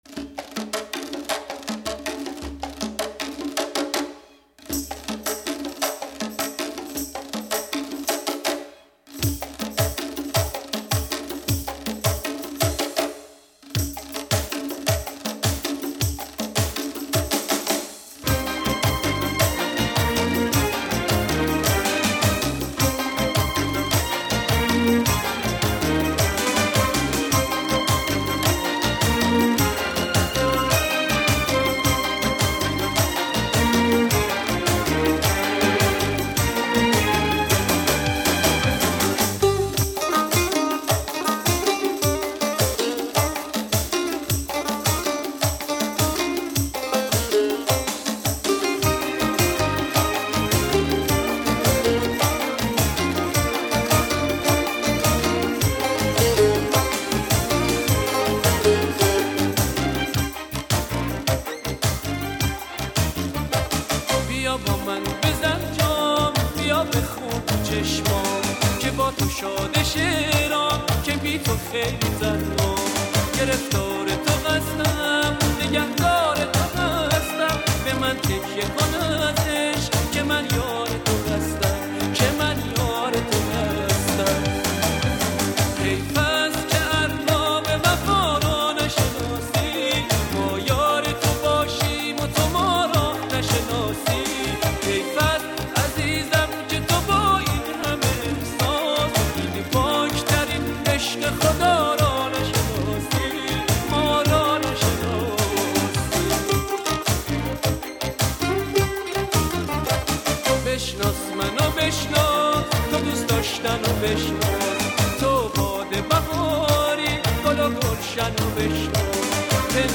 آهنگ قدیمی
آهنگ شاد قدیمی